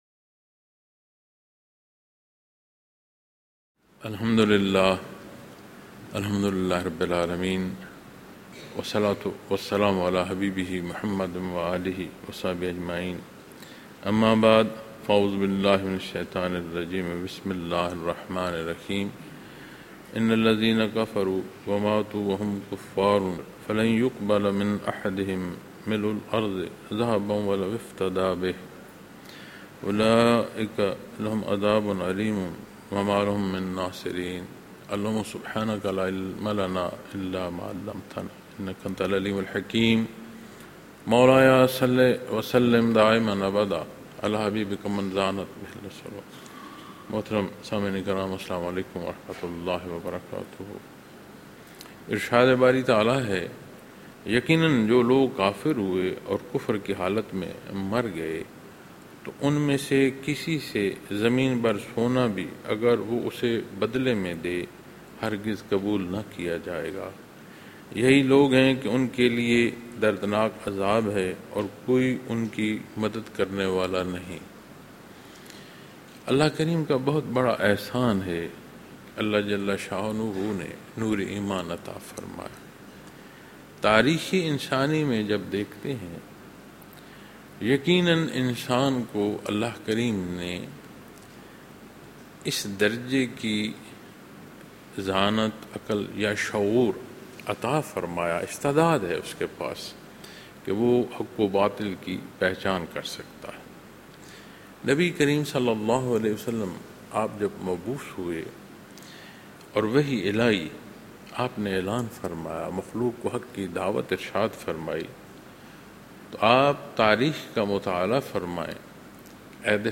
Munara, Chakwal, Pakistan